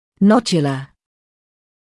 [‘nɔʤjələ][‘ноджйэлэ]узелковый; узловатый